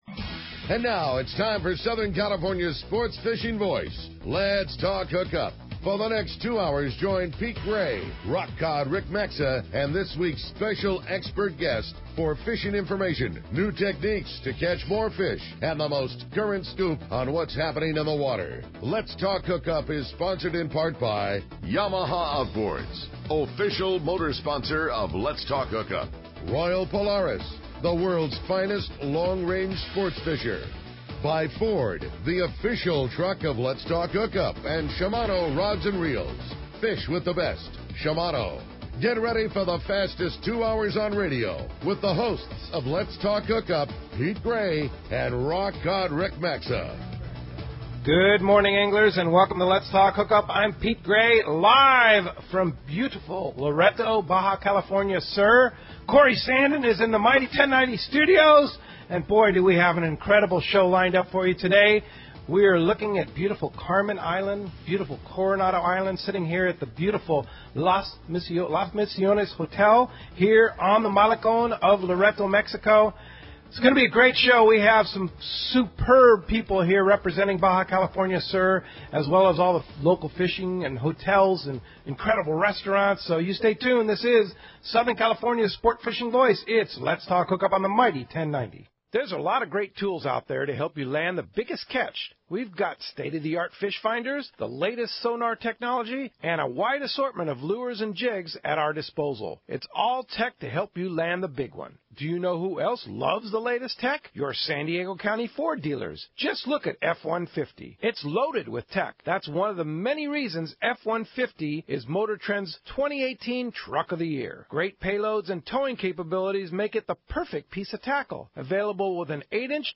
Let’s Talk Hookup Saturday 6/2/18- Live from Loreto, Baja South with the Sportfishing Association – 7-8am